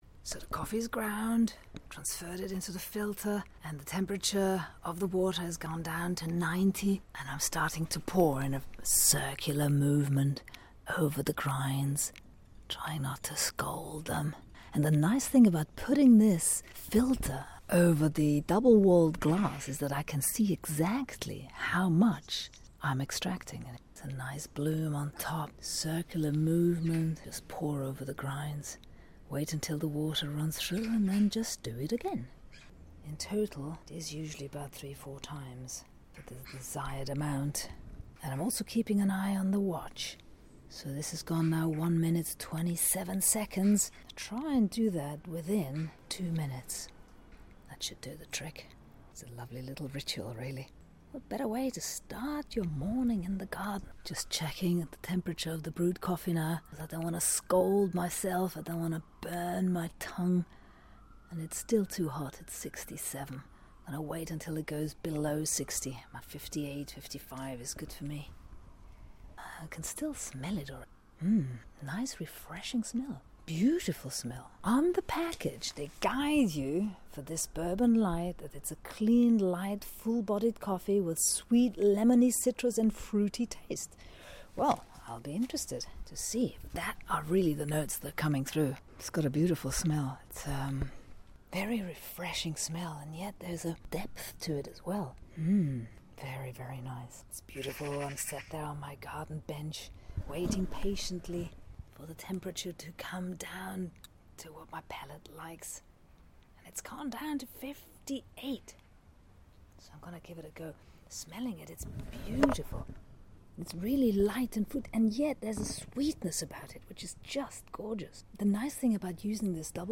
sitting in the garden on this sunny saturday in september, tasting the Bourbon light brewed as a filter coffee - ceramic miniature (1 cup only) V 60